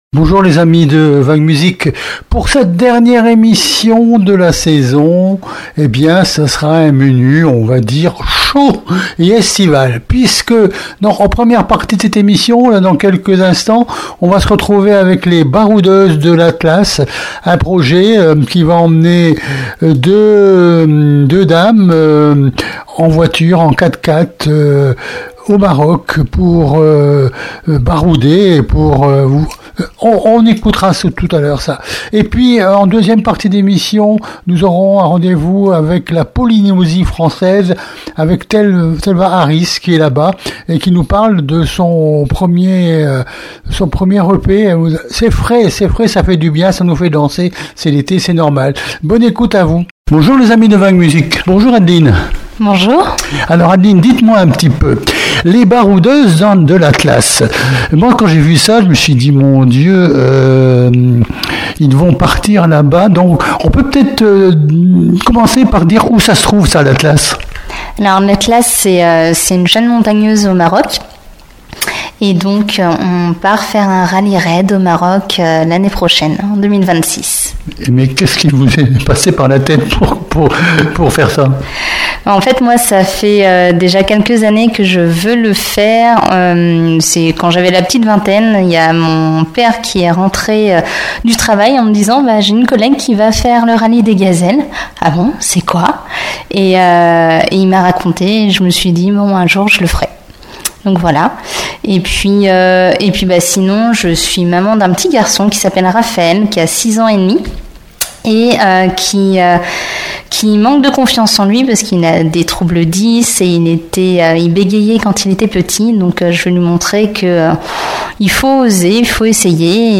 VAG MUSIC -LES BAROUDEUSES DE L'ATLAS - INTERVIEW DU 7 JUILLET 2025